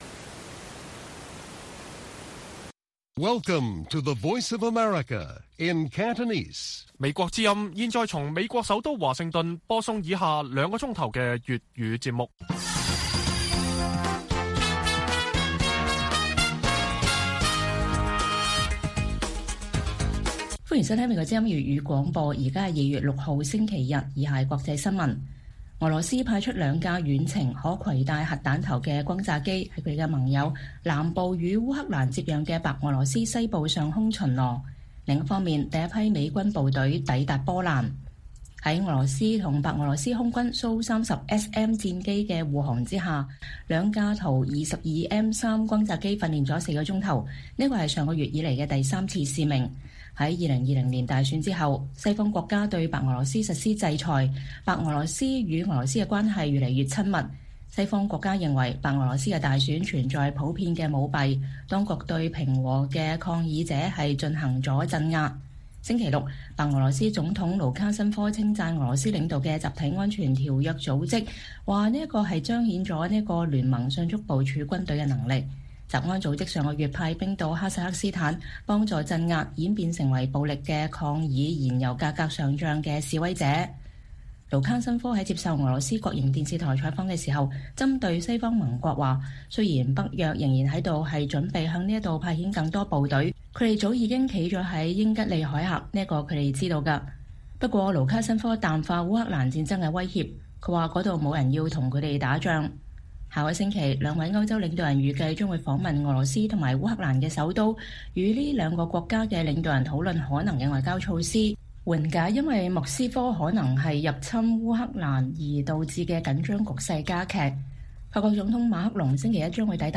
粵語新聞 晚上9-10點：俄轟炸機在白俄羅斯訓練 第一批美軍抵達波蘭